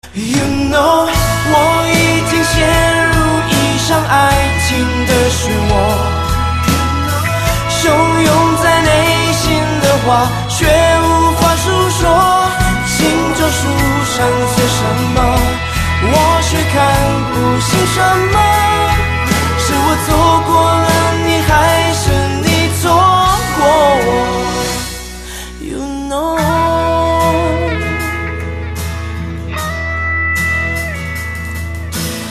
M4R铃声, MP3铃声, 华语歌曲 66 首发日期：2018-05-15 20:11 星期二